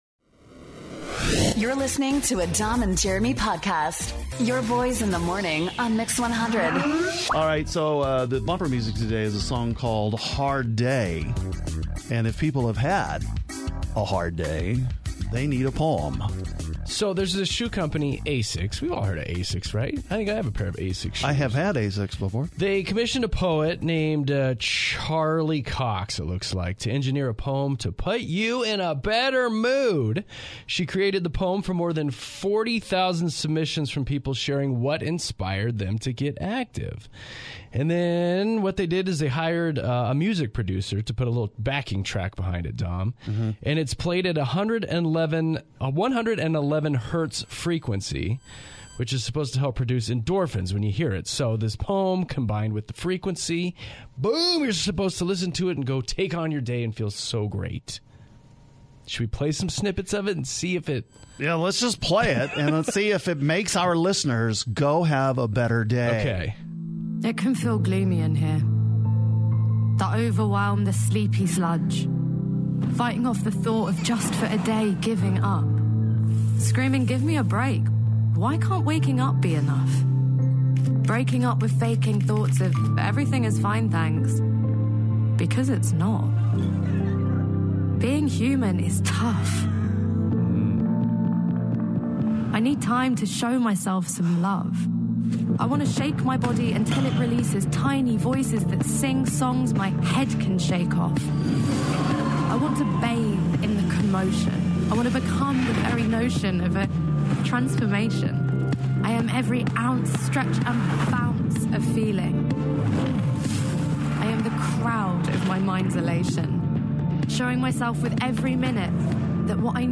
Let's all relax and listen to some peaceful sounds.